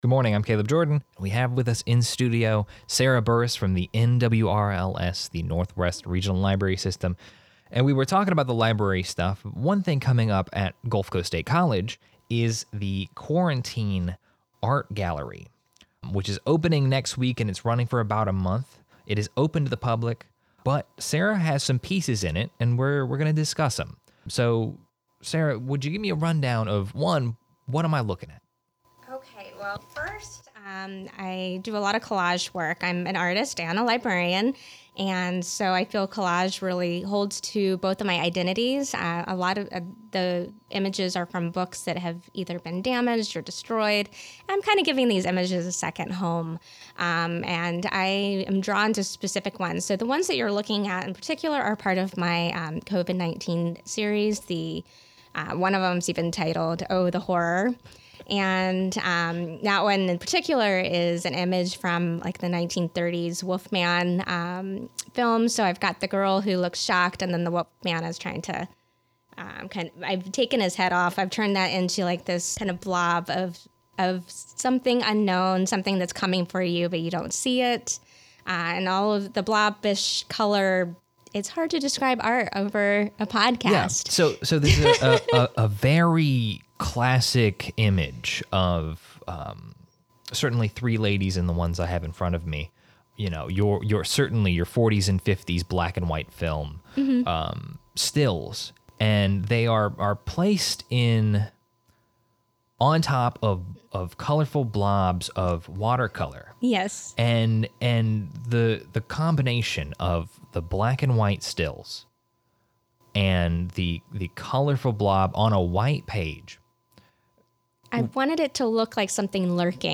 Artist Interview